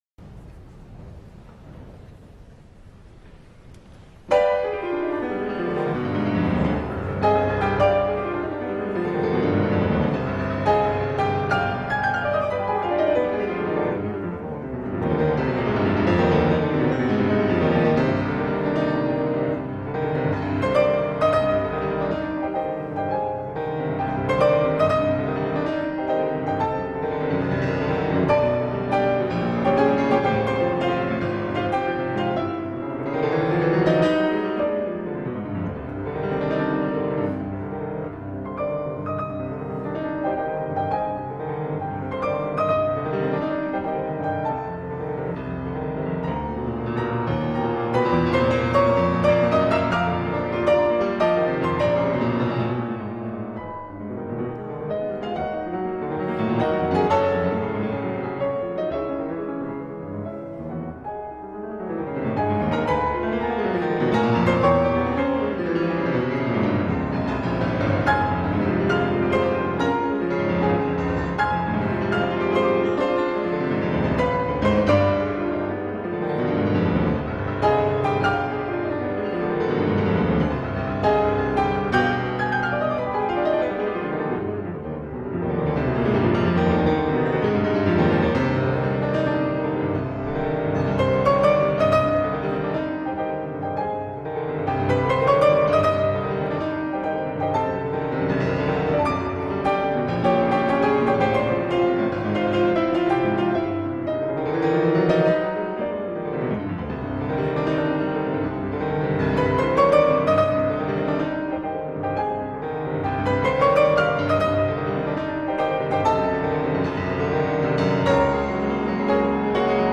The burst of the dissonant seventh chord, in the beginning, conveys a sense of tension and instability.
The excessively quick tempo from the left hand evokes anger and anxiety.
The repeated main melody in 0:38 but with a much lower dynamic conveys more than just anxiety and perseverance but also the painful struggle.
The ending of this piece uses four tonic chords of C major with fortississimo.